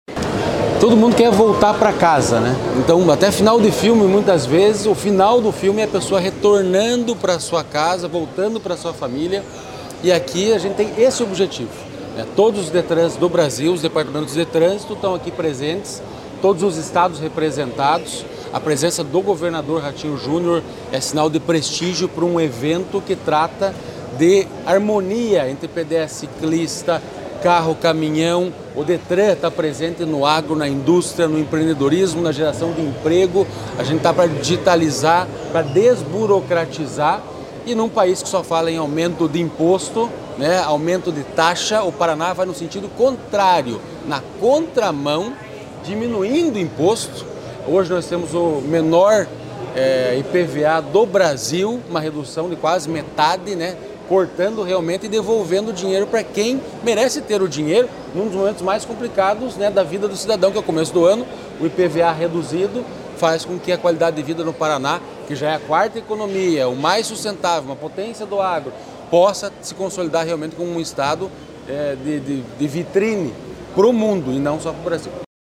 Sonora do presidente do Detran-PR, Santin Roveda, sobre a liberação de R$ 100 milhões para sinalização viária das cidades